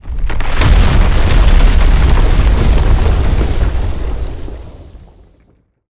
Sfx_creature_iceworm_breach_01.ogg